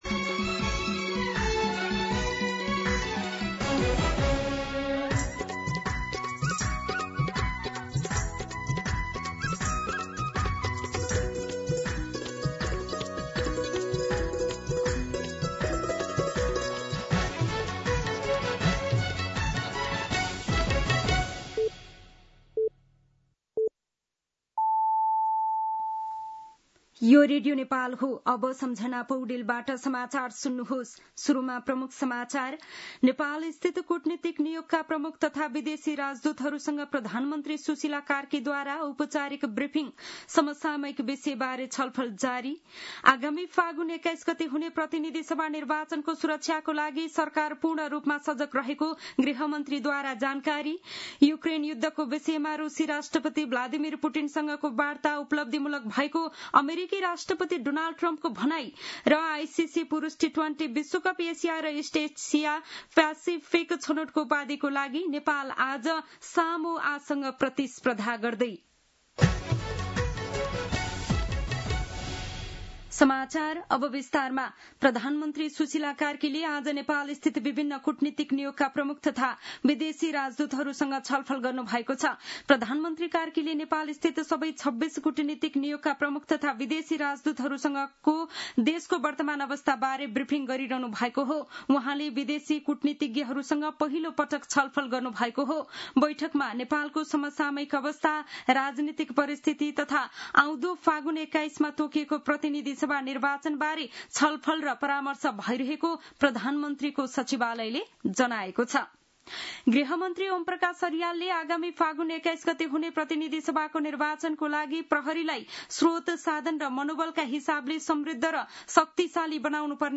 दिउँसो ३ बजेको नेपाली समाचार : ३१ असोज , २०८२
3-pm-Nepali-News-8.mp3